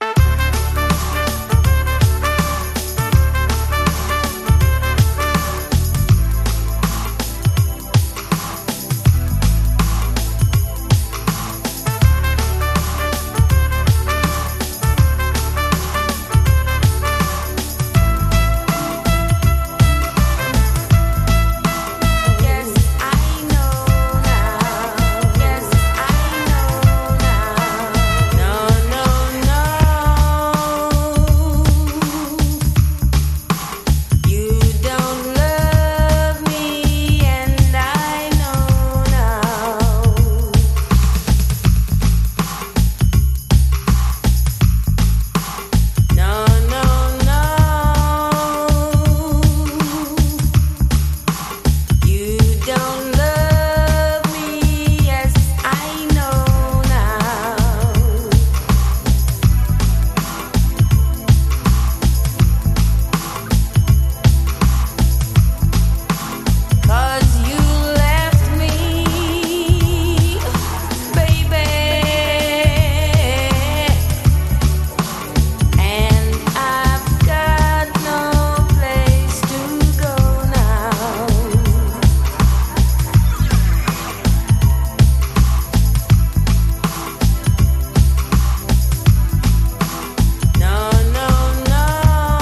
レゲエやダンスホール界のみならず、ヒップホップの現場でもプライされまくったビッグ・チューン。